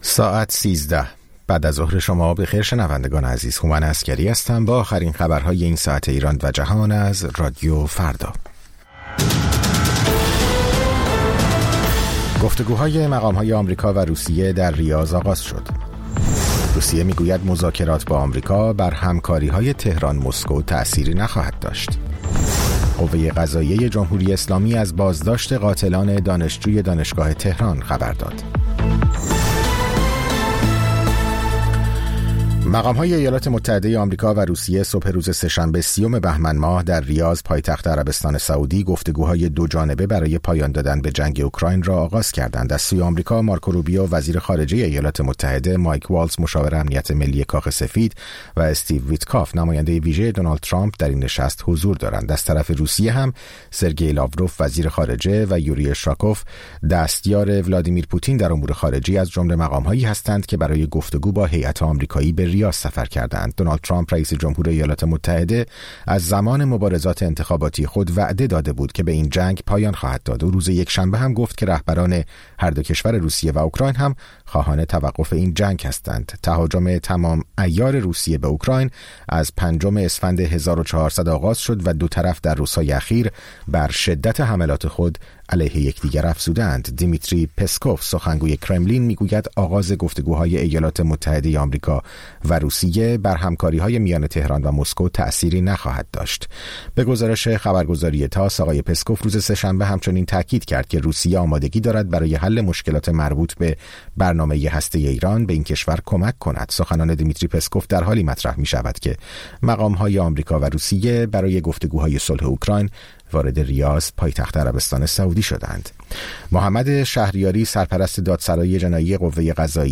سرخط خبرها ۱۳:۰۰